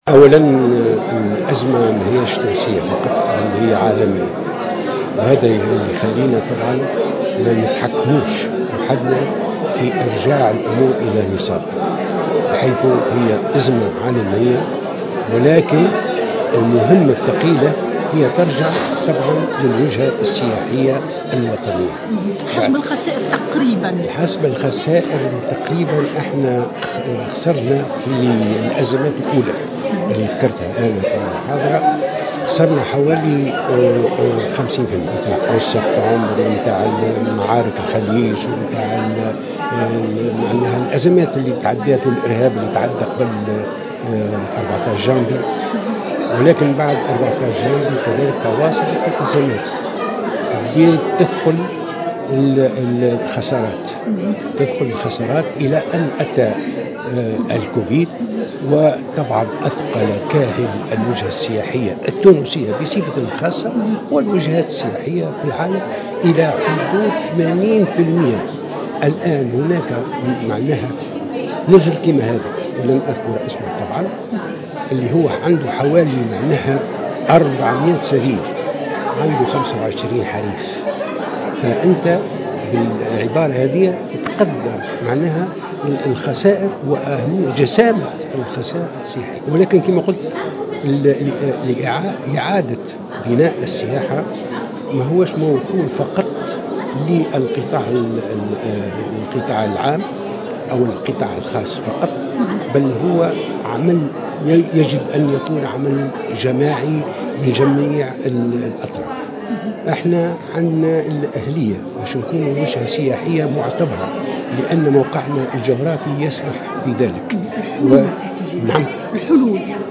وعن الحلول الممكنة لانعاش القطاع، دعا التيجاني الحدّاد في تصريحه لـ "الجوهرة أف أم" على هامش ندوة في سوسة لتسليط الضوء على الحلول الممكنة للنهوض بقطاع السياحة بعد جائحة كوفيد، دعا الى اعادة جدولة ديون القطاع السياحي من فنادق ووكالات أسفار ومطاعم سياحية، والعمل الجدي على توظيف البيئة من خلال حملة وطنية بمشاركة الجميع لارجاع البيئة التونسية والسياحة الى سالف عهدهما.